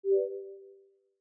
Звуки MacBook, iMac
Звук удаления файла или папки на Mac